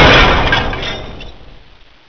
game-source/ParoxysmII/sound/ambience/metbrk.wav at master